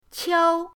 qiao1.mp3